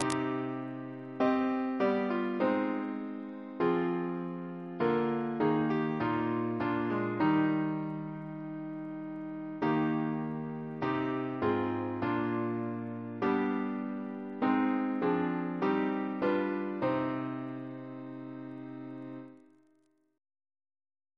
Double chant in C minor Composer: Robert Cooke (1768-1814), Organist of Westminster Abbey Reference psalters: ACB: 267; OCB: 340; PP/SNCB: 140; RSCM: 33